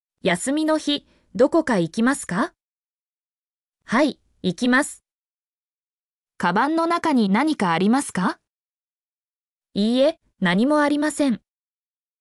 mp3-output-ttsfreedotcom-12_lgDlULUQ.mp3